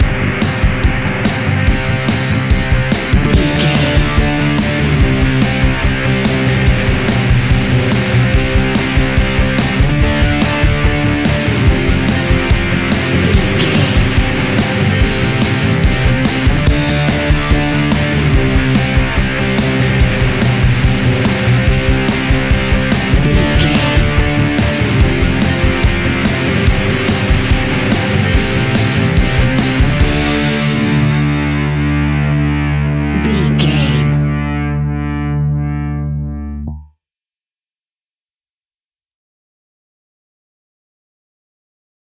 Epic / Action
Fast paced
Mixolydian
hard rock
blues rock
instrumentals
Rock Bass
heavy drums
distorted guitars
hammond organ